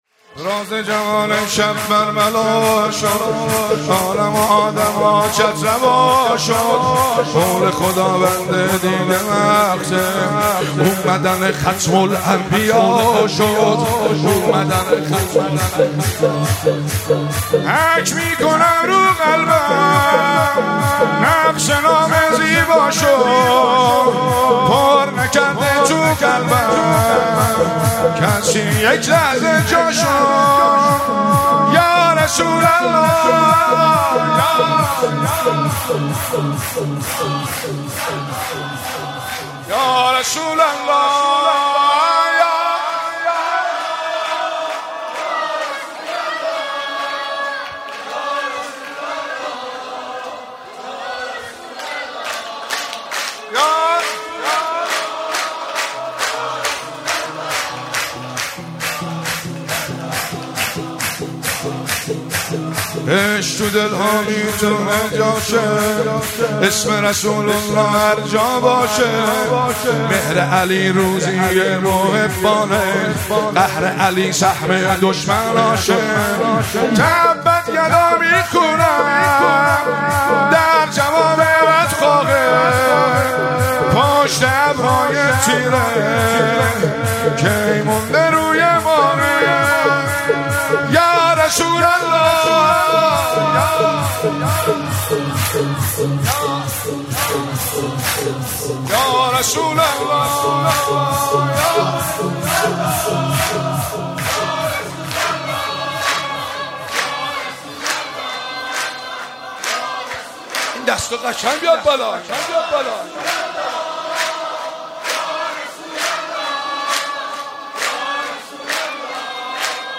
سرود- راز جهان امشب برملا شد
جشن میلاد حضرت رسول اکرم (ص) و امام صادق (ع)- مهر 1401
جشن میلاد حضرت رسول اکرم (ص) و امام صادق (ع)- مهر 1401 دانلود عنوان سرود- راز جهان امشب برملا شد تاریخ اجرا 1401-07-19 اجرا کننده مناسبت ولادت موضوع رسول اکرم(ص) نوع صوت شعرخوانی مدت زمان صوت 00:04:48